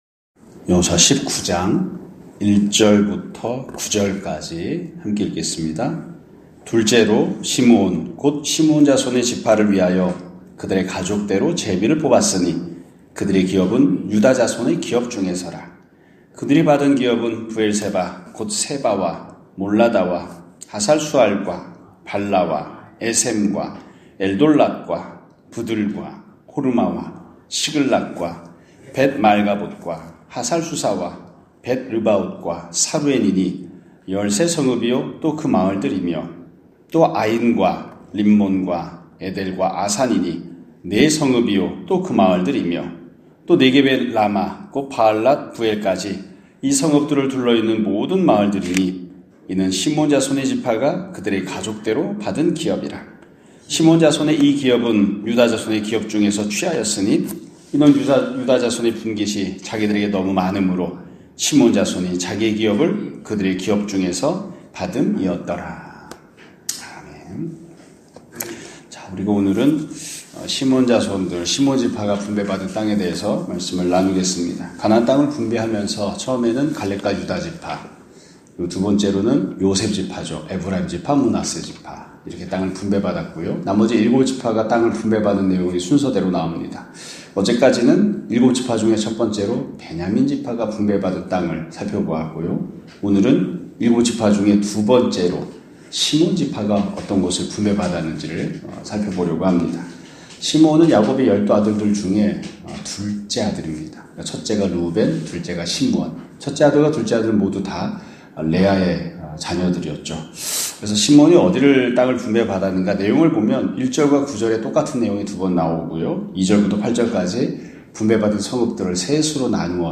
2024년 12월 31일(화요일) <아침예배> 설교입니다.